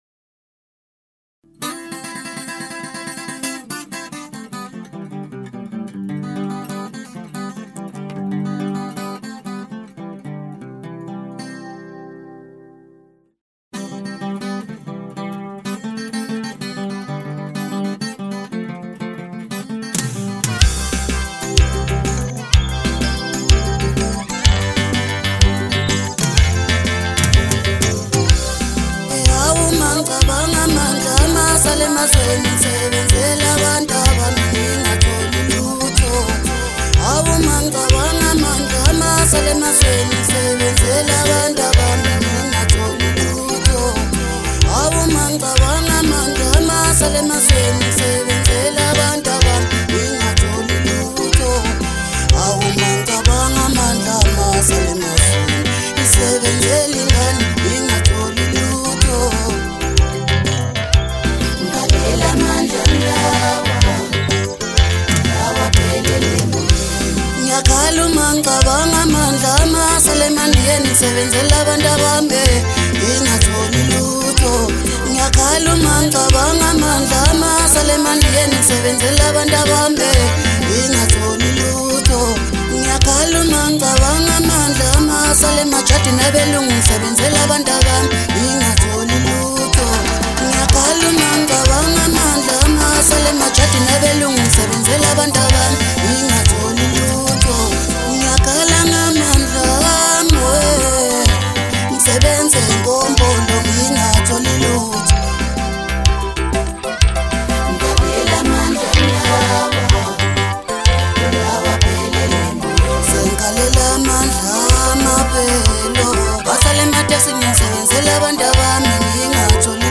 MASKANDI MUSIC
hit maskandi song